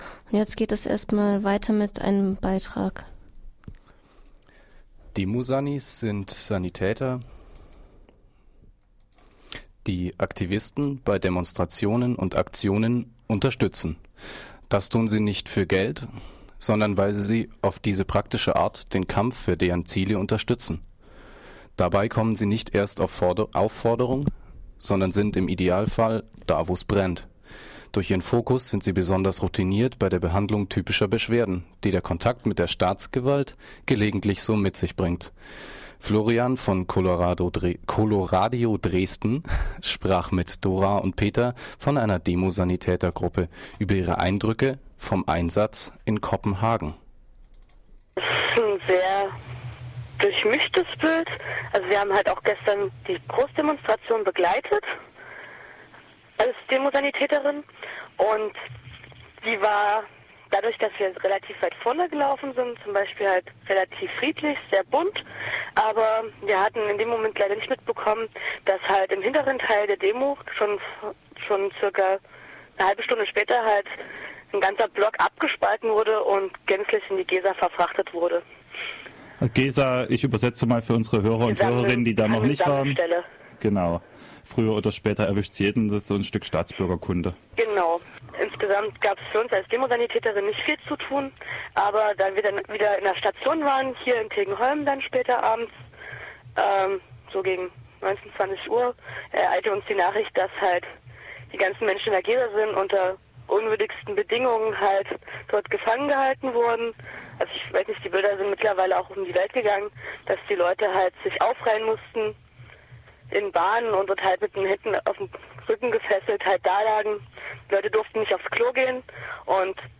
Interview mit zwei Demosanis in kopenhagen (COP15)